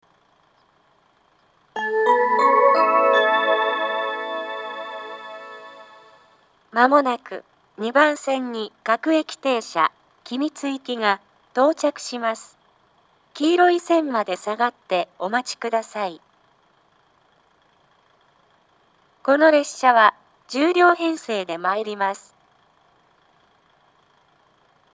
２番線接近放送 各駅停車君津行（１０両）の放送です。